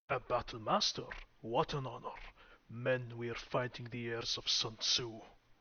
Subject description: My personal VO set   Reply with quote  Mark this post and the followings unread